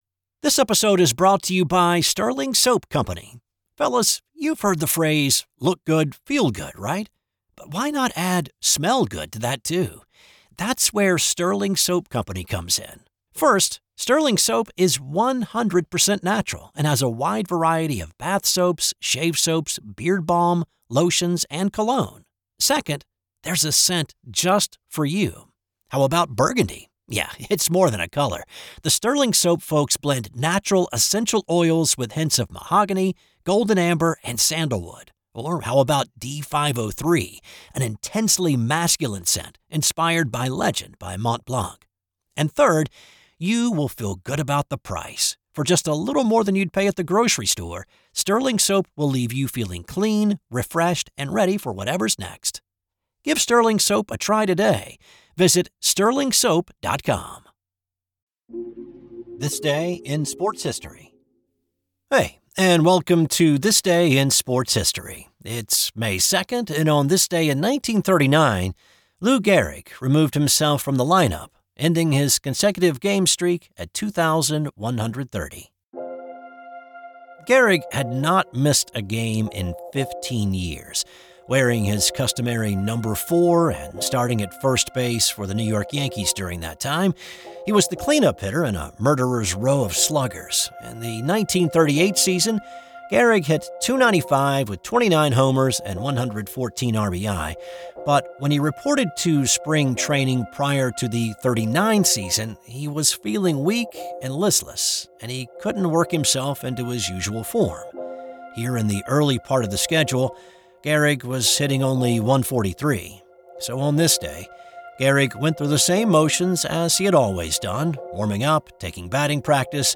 I research, write, voice, and produce each show.